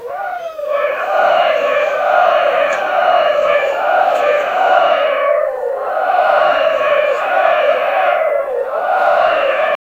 A covenant of old witches casting eerie spells by a gorgeous goddess